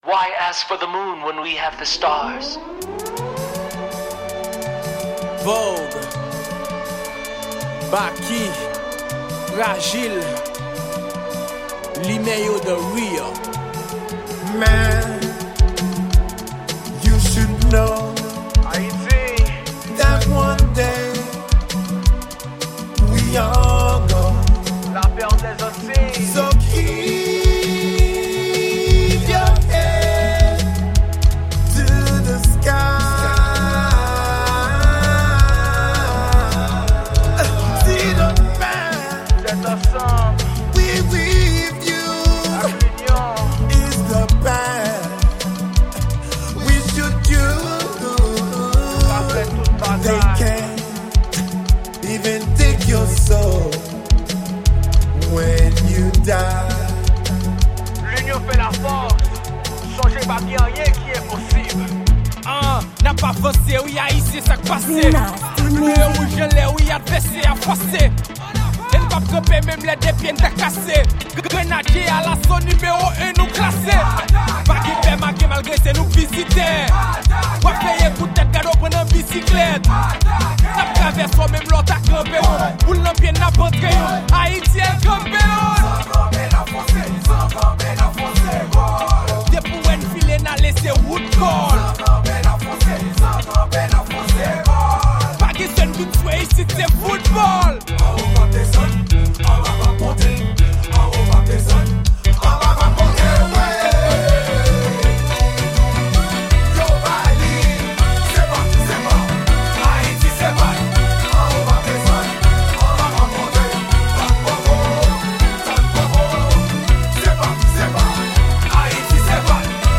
Genre: World Music.